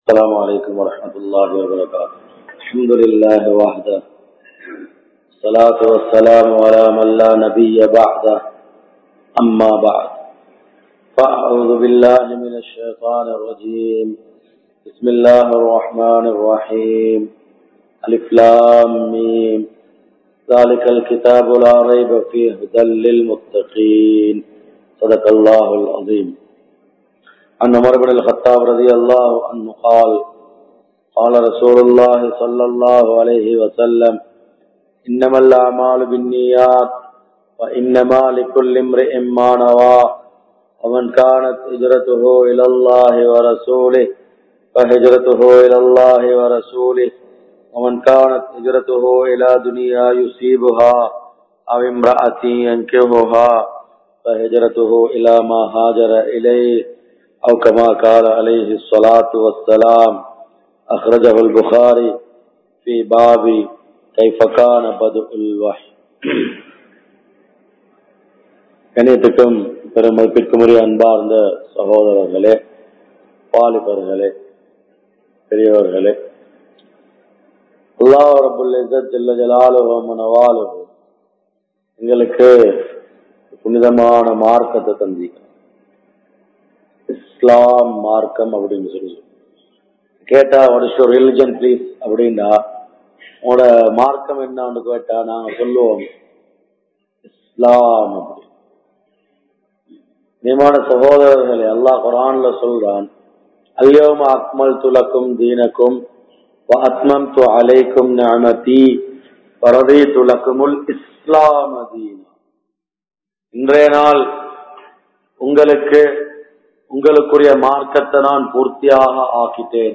Kulanthaihalai Seeraha Vali Nadaaththungal (குழந்தைகளை சீராக வழிநடாத்துங்கள்) | Audio Bayans | All Ceylon Muslim Youth Community | Addalaichenai
Kurunegala, Aswedduma Jumua Masjidh